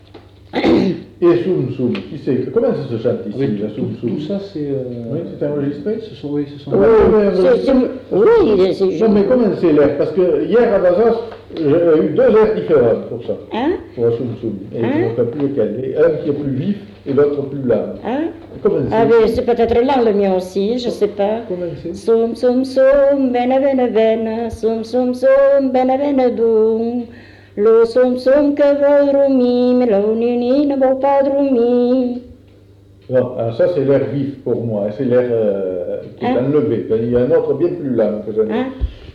Lieu : Cazalis
Genre : chant
Type de voix : voix de femme
Production du son : chanté
Classification : som-soms, nénies